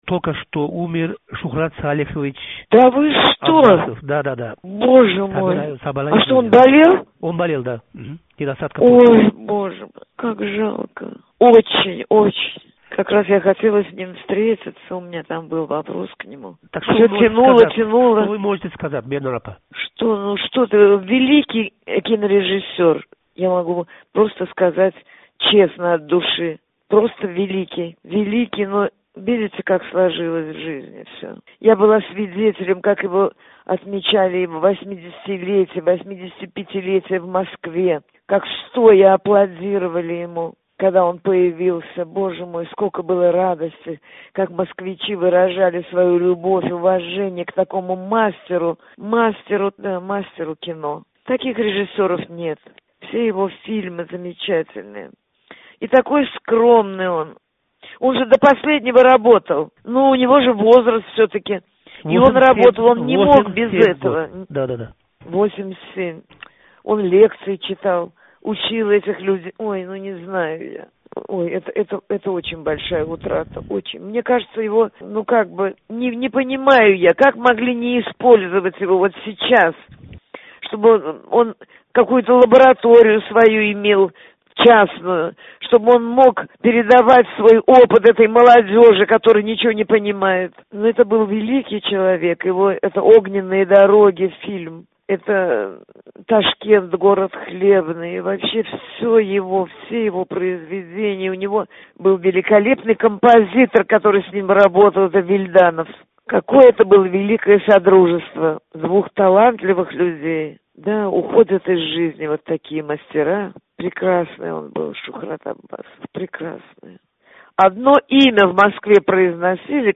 СССР халқ артисти Бернора Қориева таъзияси